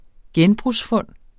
Udtale [ ˈgεnbʁus- ]